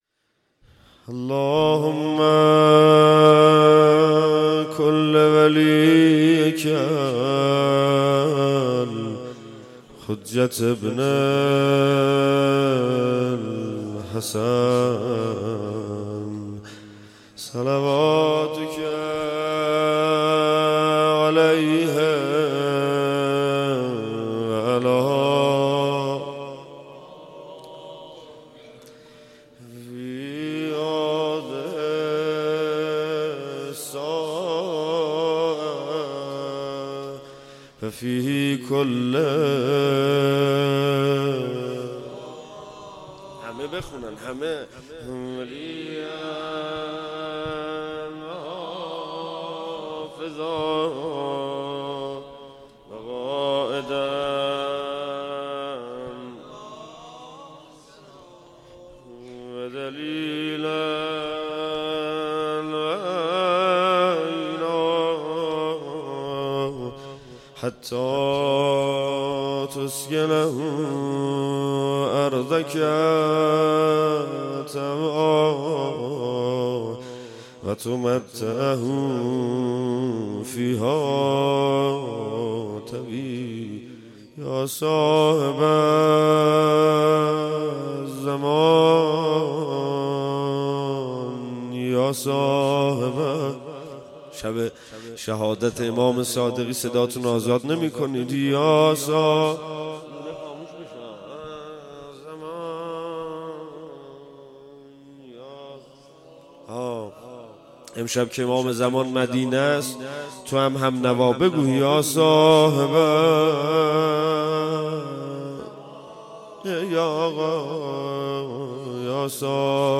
22 اُمین محفل بهشتی (شهادت امام صادق سلام الله علیه)، 28 تیر 1396، پاسداران، بلوار شهیدان شاه حسینی، مسجد حضرت صاحب الزمان عجل الله تعالی فرجه